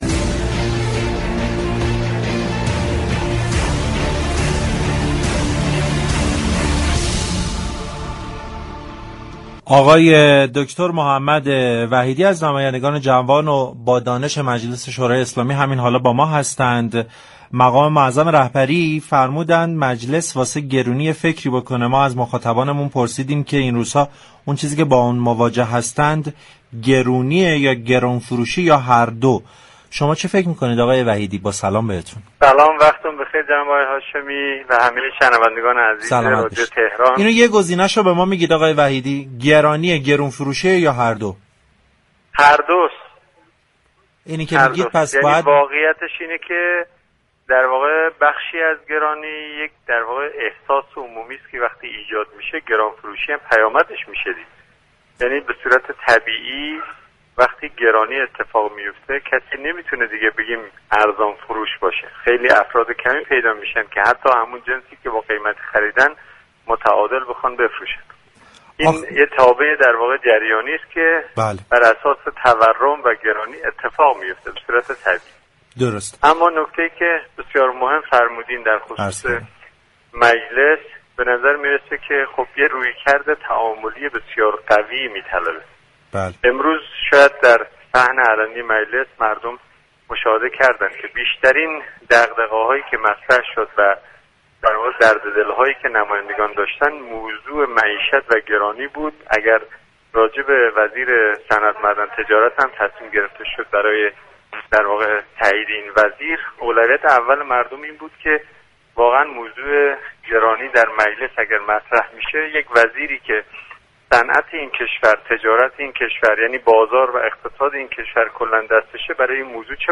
دكتر محمد وحیدی، نماینده مجلس شورای اسلامی گفت:طرح كالابرگ برای كمك به دهك های پایین جامعه تصویب شده و منابع مالی آن از درآمدهای سازمان هدفمندی یارانه‌ها تامین می‌شود.